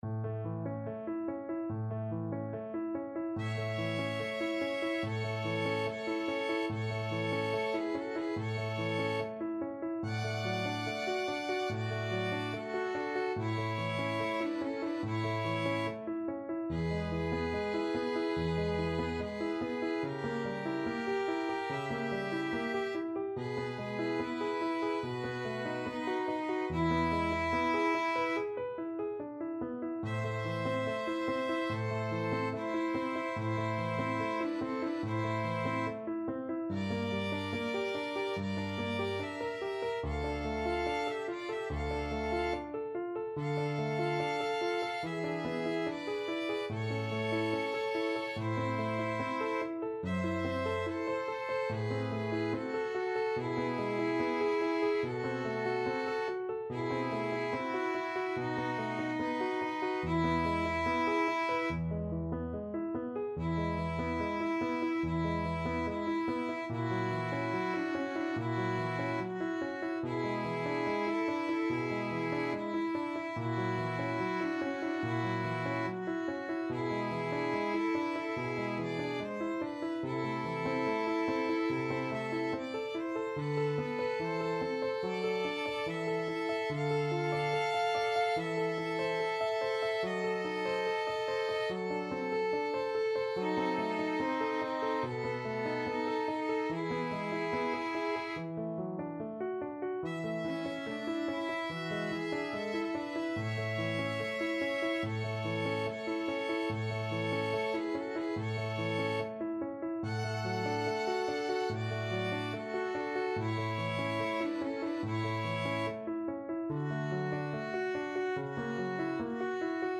Free Sheet music for Violin Duet
A major (Sounding Pitch) (View more A major Music for Violin Duet )
Andante =72
4/4 (View more 4/4 Music)
Classical (View more Classical Violin Duet Music)